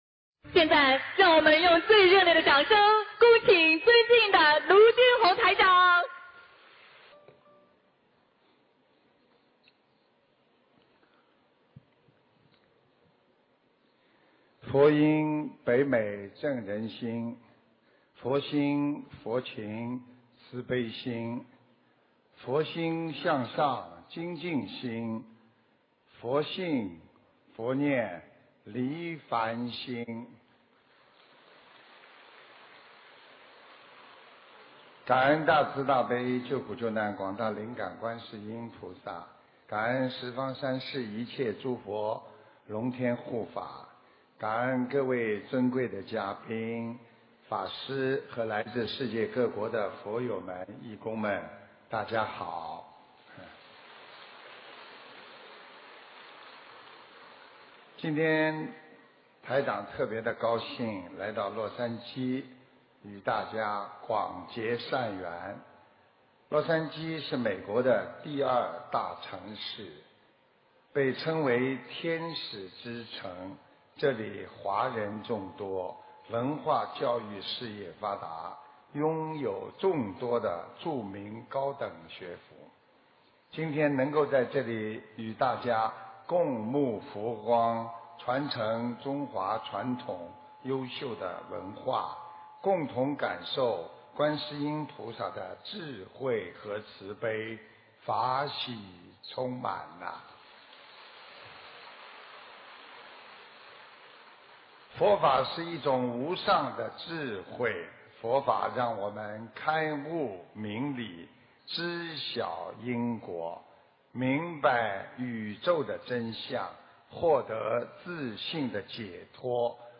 历年法会开示音频 自动顺序播放 - 历年法会【音|文】 慈爱心灵